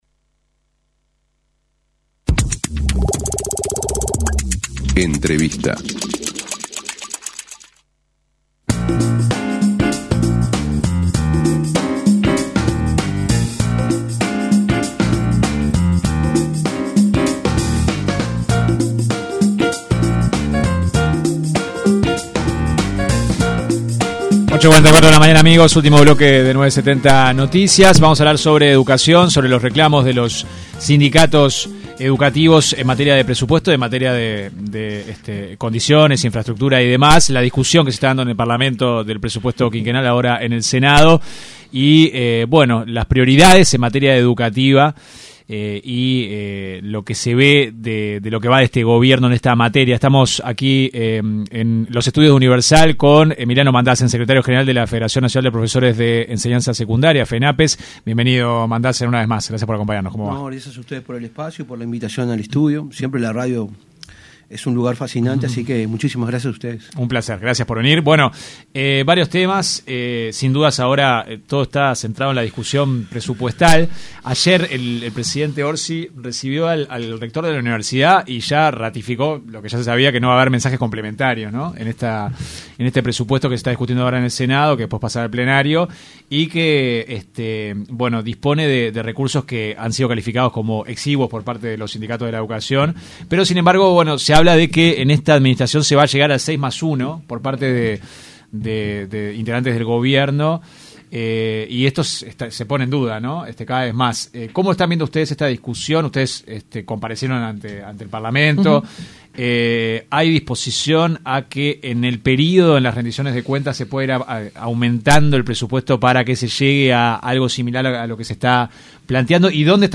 en diálogo con 970 Noticias